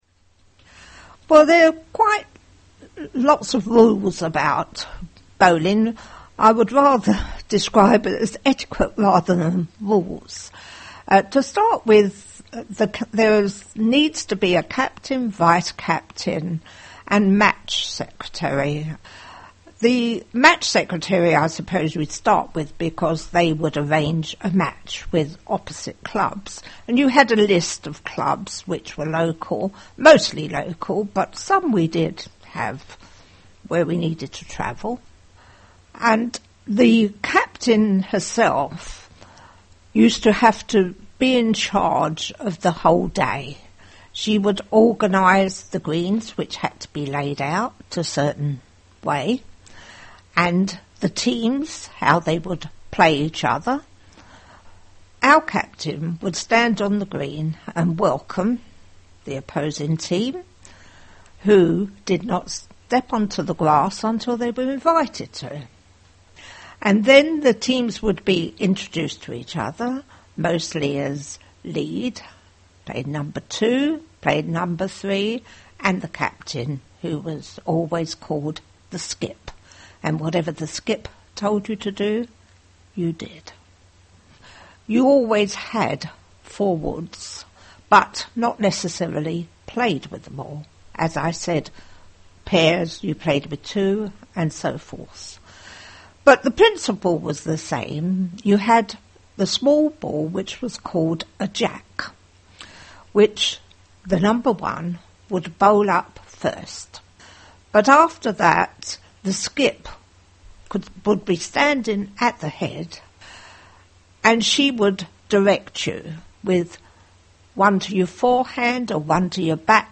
talking about bowling.